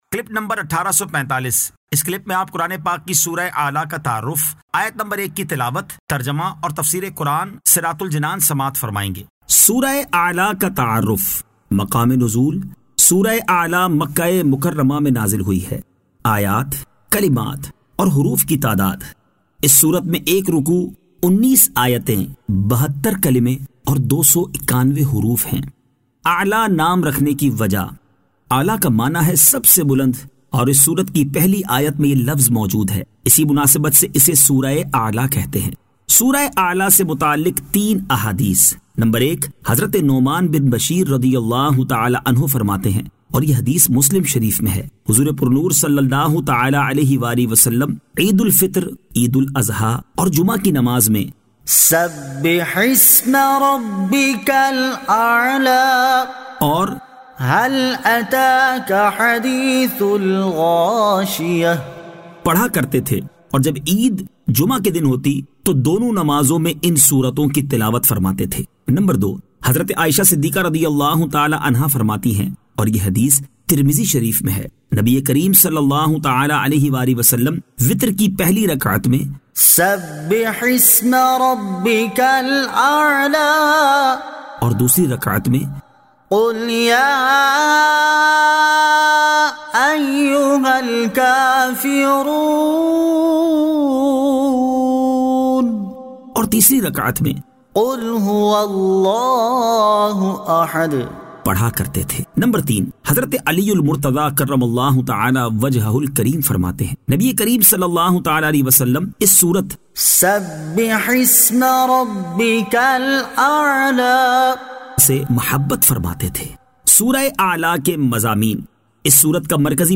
Surah Al-A'la 01 To 01 Tilawat , Tarjama , Tafseer